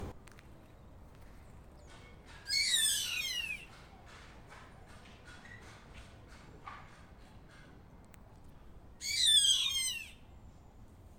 Chimango Caracara (Daptrius chimango)
Asediados por un Tordo Musico.
Life Stage: Adult
Location or protected area: Gran Buenos Aires Norte
Condition: Wild
Certainty: Observed, Recorded vocal